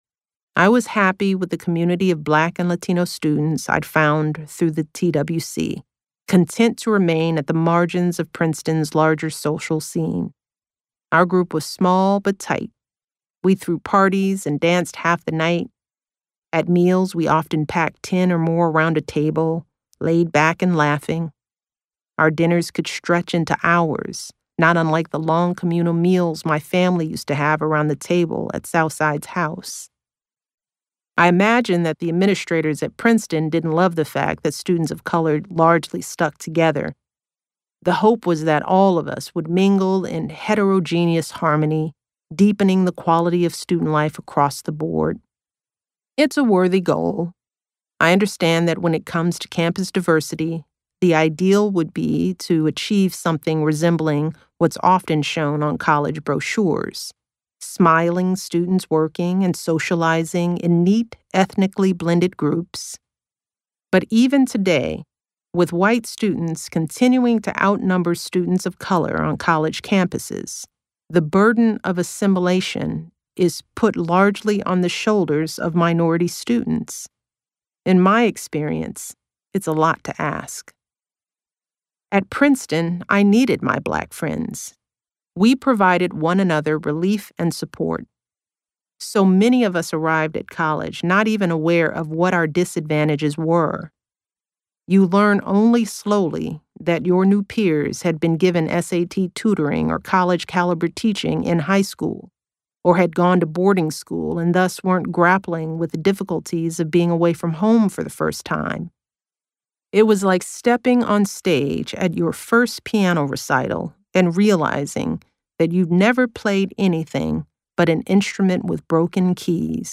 EXCLUSIVE: Michelle Obama Reads From Her Forthcoming Memoir 'Becoming'
In two audio clips from her memoir, set to hit shelves Tuesday, the former first lady reads about her life at Princeton and about her difficulties having a baby.